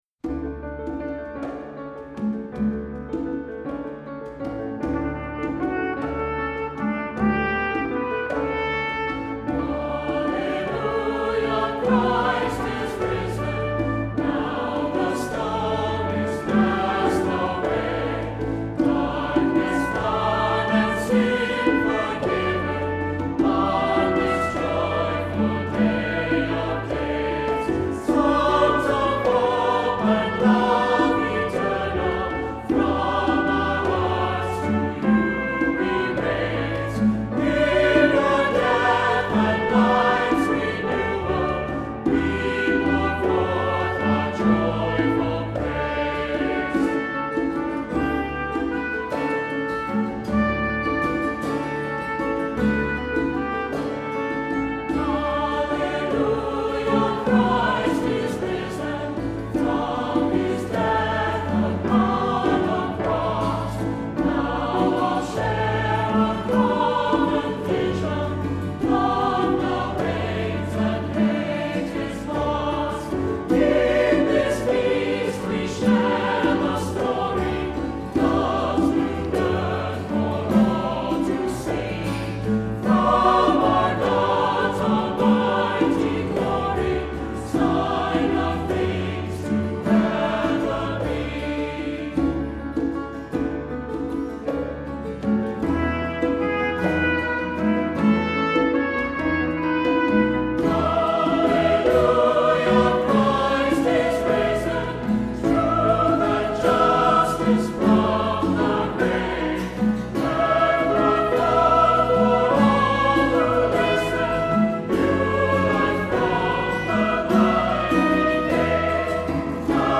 Accompaniment:      Keyboard
Music Category:      Christian